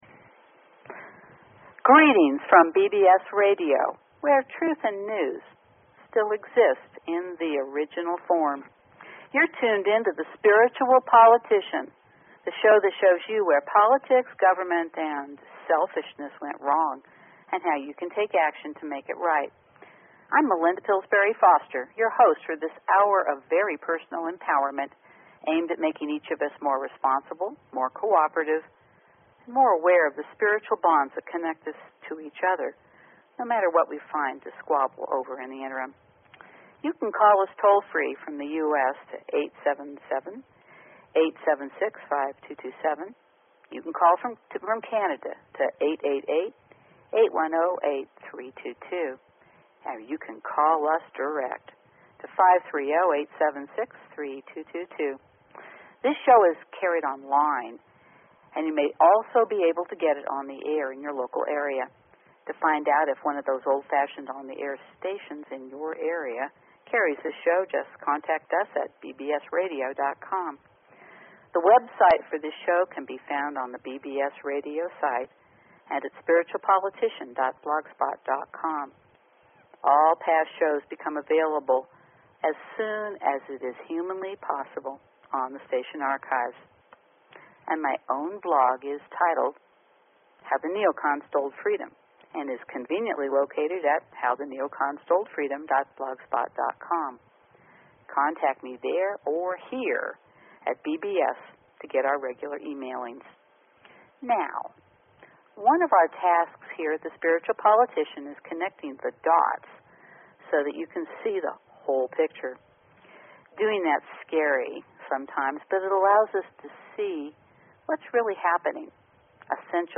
Talk Show Episode, Audio Podcast, Spiritual_Politician and Courtesy of BBS Radio on , show guests , about , categorized as